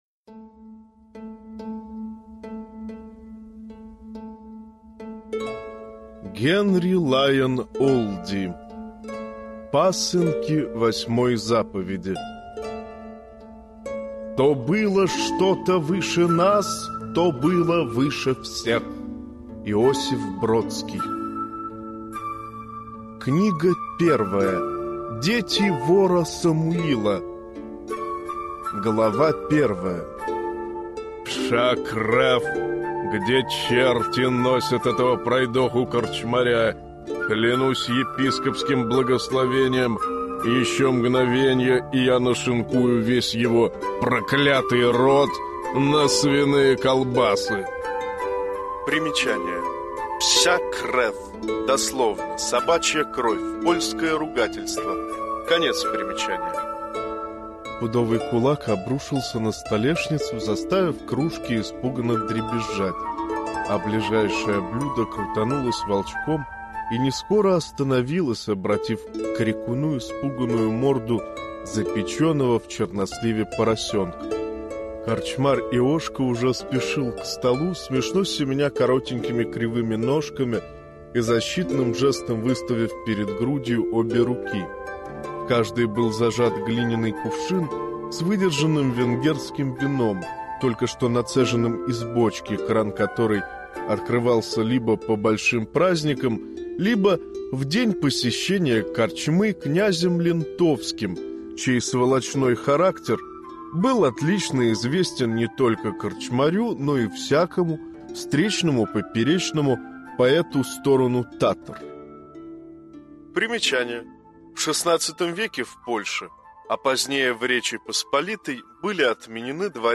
Аудиокнига Пасынки восьмой заповеди | Библиотека аудиокниг
Прослушать и бесплатно скачать фрагмент аудиокниги